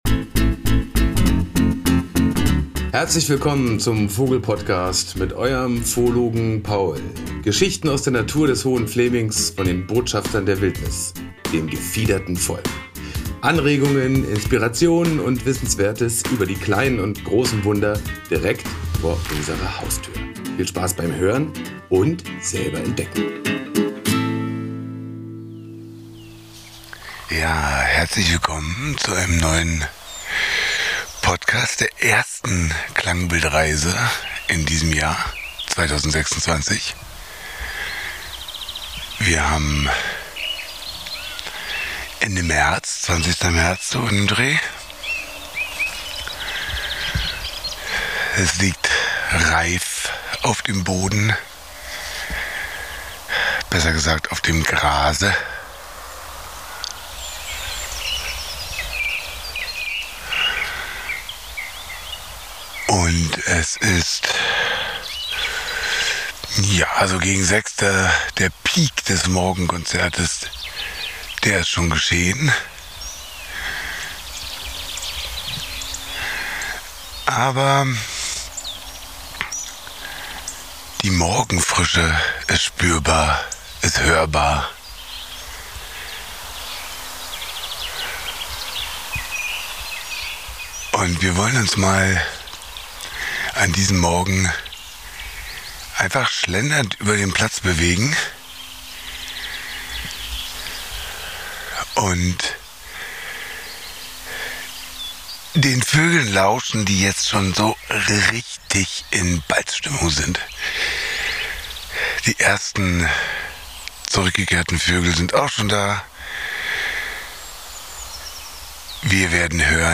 Beschreibung vor 1 Woche (Empfehlung: Klangbildreise mit Kopfhörern genießen!)
Für mich eines der schönsten Kräftemessen in der Tierwelt, denn Singvögel zeigen Stärke durch Gesang und Klang.
Die Gesänge sind in dieser Zeit virtuoser, experimenteller und so voller Energie, wie wir es im weiteren Jahresverlauf selten zu hören bekommen.